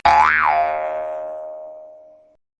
Play Cartoon Jump - SoundBoardGuy
Play, download and share Cartoon Jump original sound button!!!!
cartoon-jump-sound-effect-funny-sound-effects.mp3